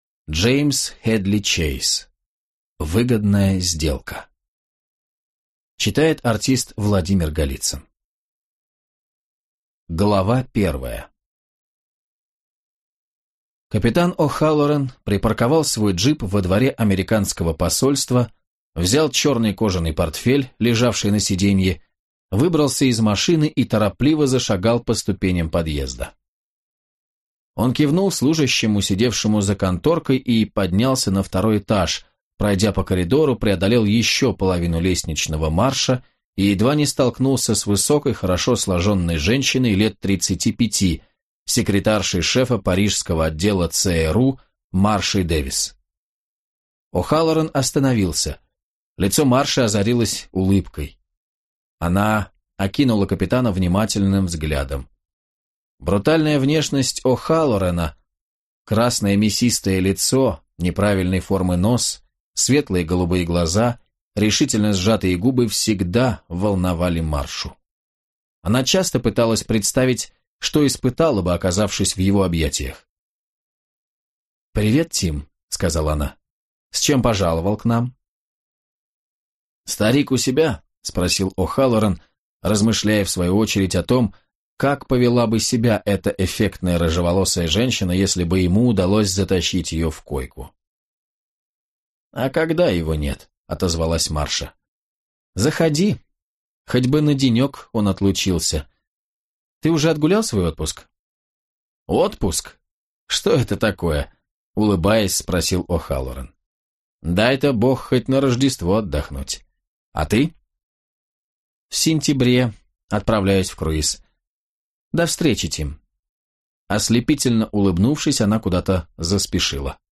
Аудиокнига Выгодная сделка | Библиотека аудиокниг
Прослушать и бесплатно скачать фрагмент аудиокниги